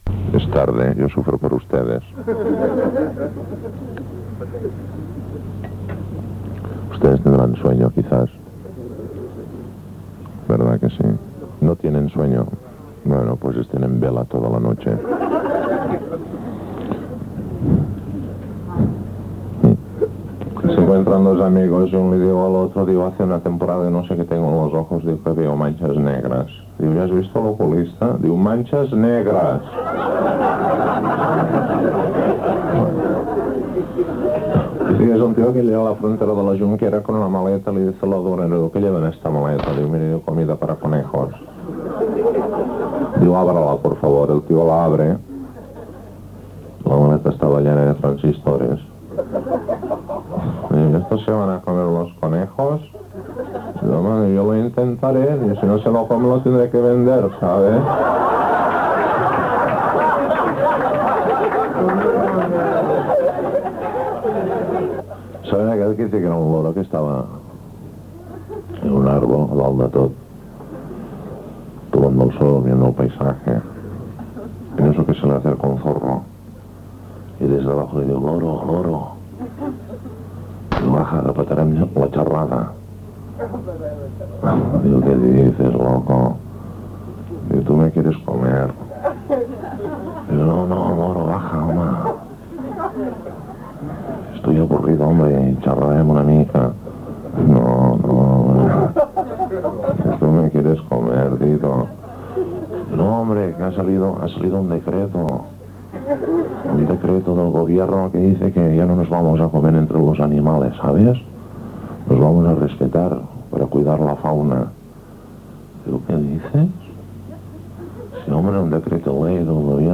Acudits d'Eugenio a la seva secció del programa
Info-entreteniment